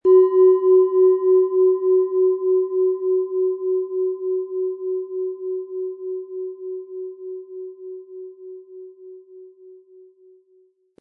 Planetenschale® Sensibel und Einfühlend sein & Geborgen fühlen mit Mond, Ø 15,6 cm, 400-500 Gramm inkl. Klöppel
Planetenton 1
Nach uralter Tradition von Hand getriebene Planetenklangschale Mond.
Spielen Sie die Schale mit dem kostenfrei beigelegten Klöppel sanft an und sie wird wohltuend erklingen.
MaterialBronze